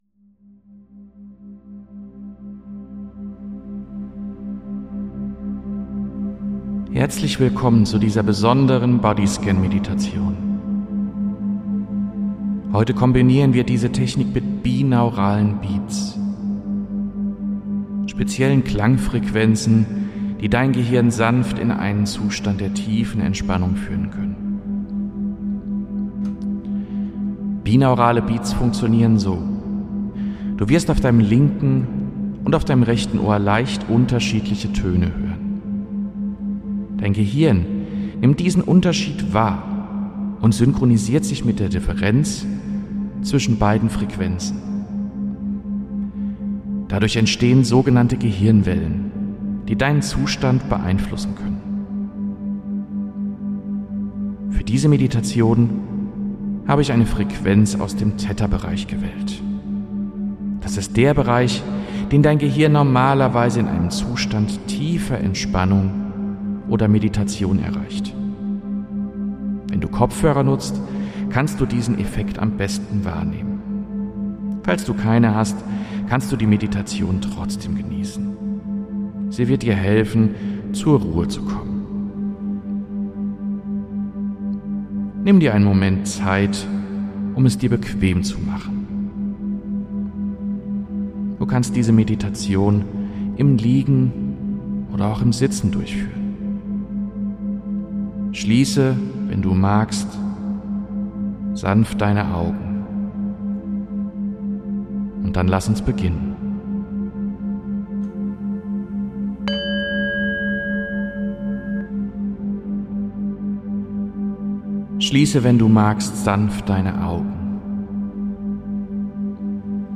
Bonus: Body-Scan mit binauralen Beats